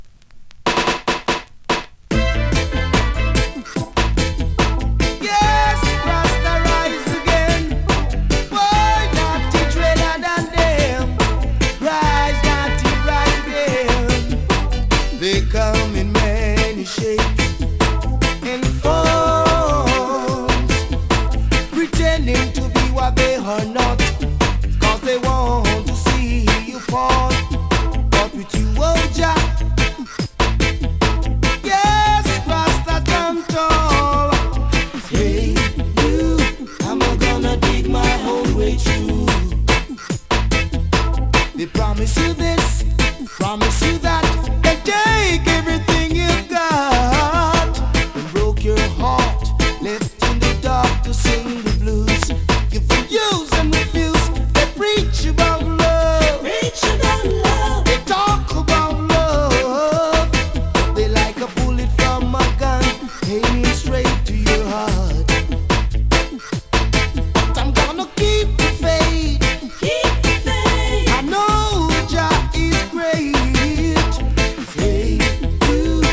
DANCEHALL!!